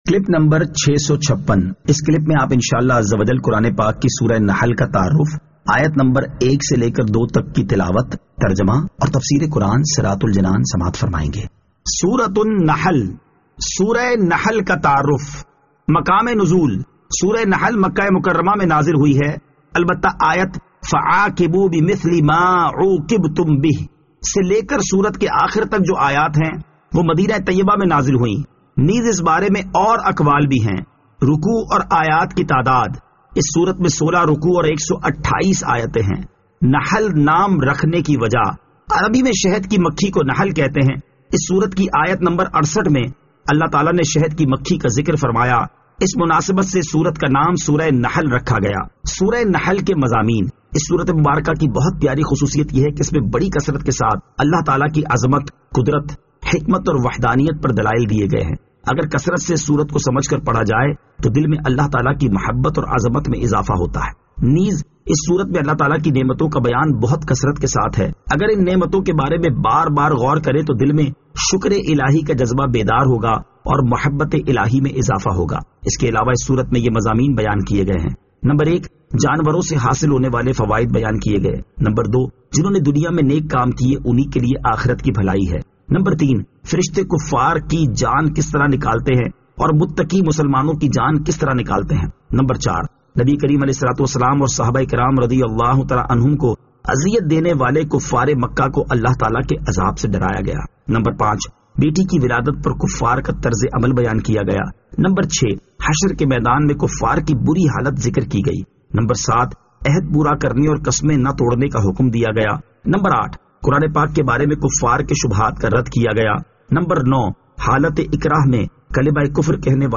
Surah An-Nahl Ayat 01 To 02 Tilawat , Tarjama , Tafseer